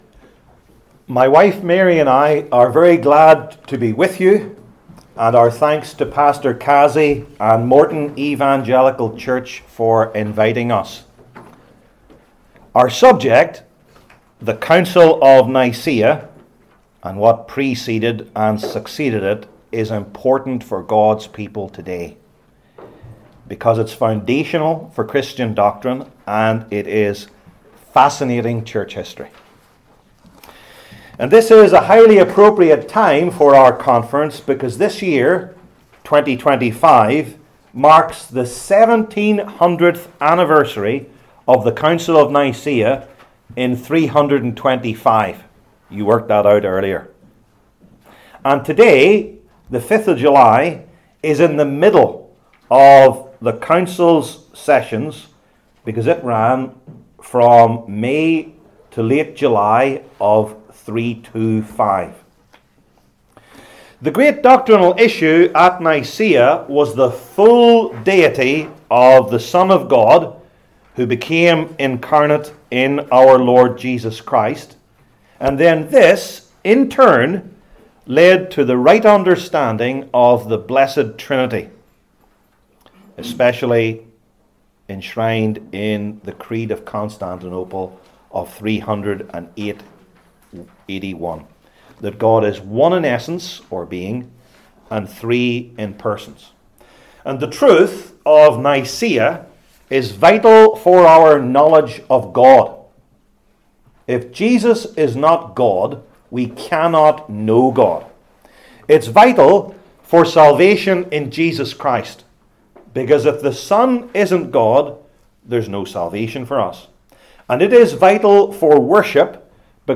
Service Type: Lectures/Debates/Interviews